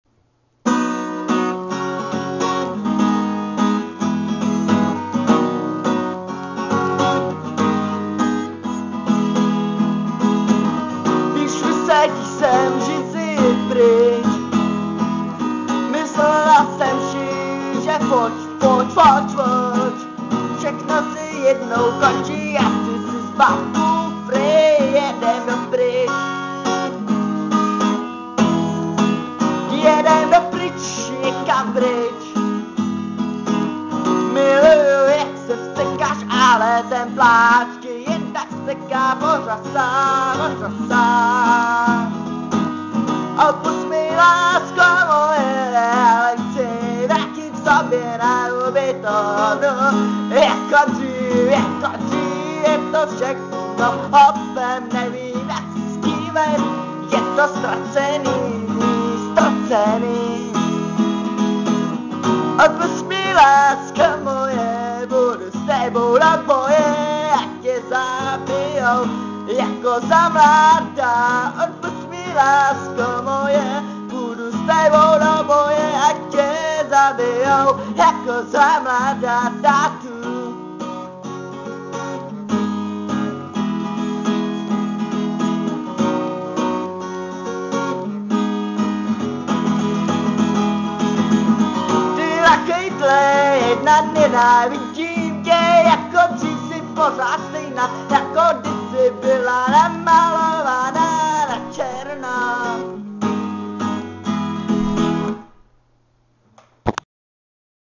Žánr: World music/Ethno/Folk
Folkové hudební album